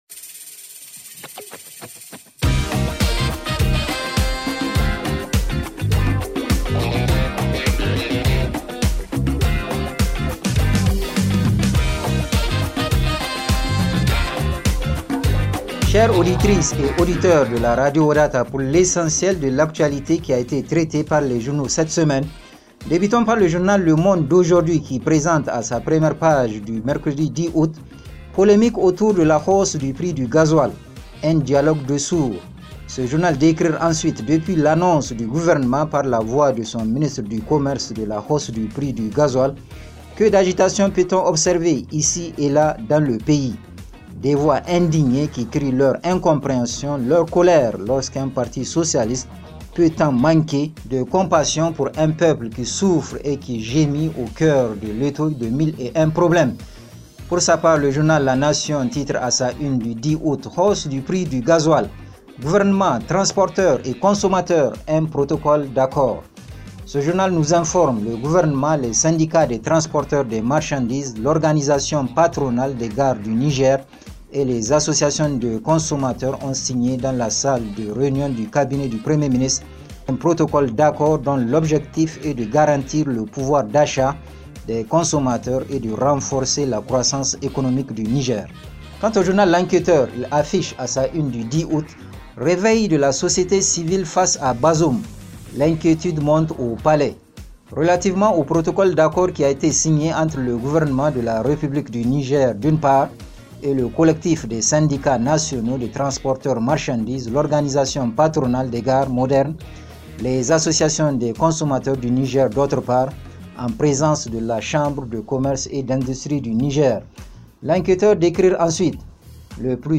Revue de presse en français